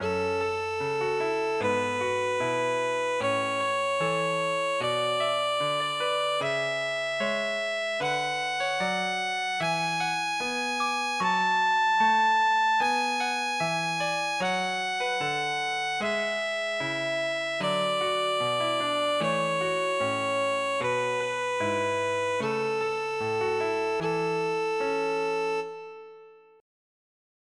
Kleines Übungsstück 2 in A-Dur für Violine
Violine mit Klavierbegleitung
Digitalpiano Casio CDP-130
Yamaha Silent Violin SV150 mit Evah Pirazzi Gold